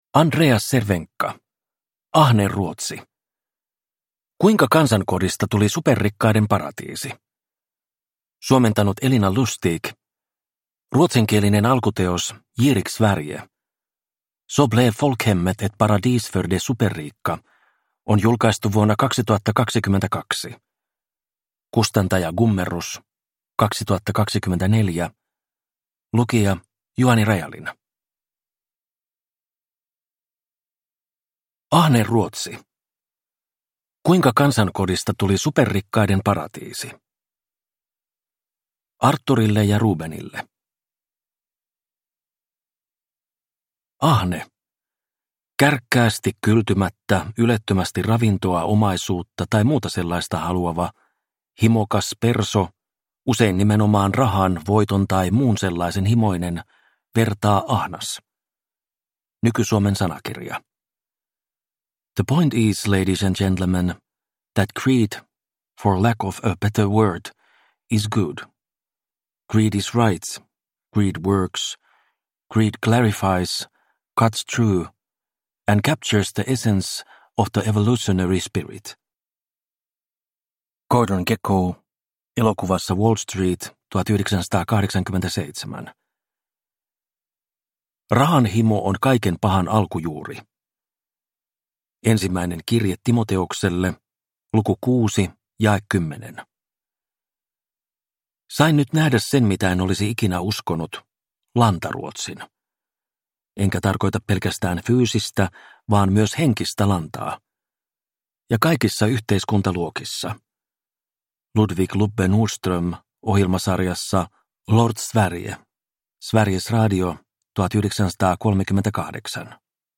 Ahne Ruotsi – Ljudbok